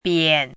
“贬”读音
biǎn
贬字注音：ㄅㄧㄢˇ
国际音标：piæn˨˩˦